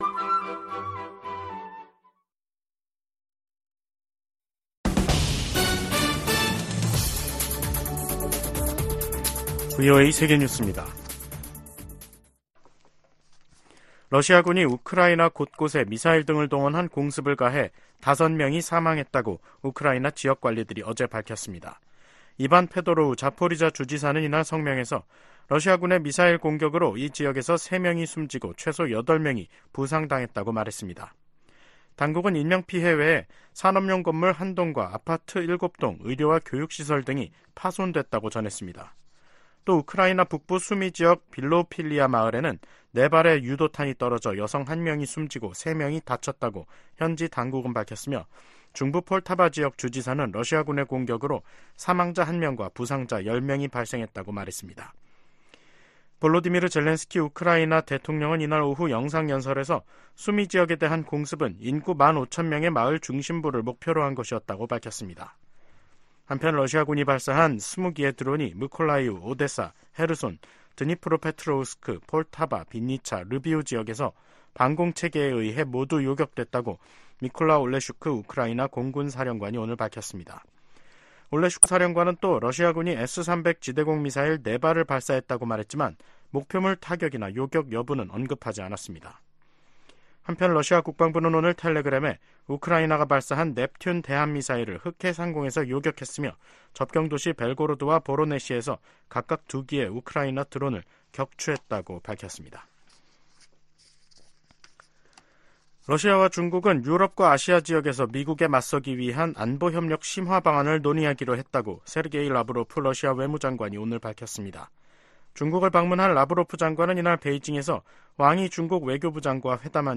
VOA 한국어 간판 뉴스 프로그램 '뉴스 투데이', 2024년 4월 9일 2부 방송입니다. 10일 백악관에서 열리는 미일 정상회담이 두 나라 관계의 새 시대를 여는 첫 장이 될 것이라고 주일 미국대사가 말했습니다. 미국 하원의 일본계 중진의원은 미일 동맹이 역대 최고 수준이라며 이번 주 미일 정상회담에 대한 큰 기대를 나타냈습니다.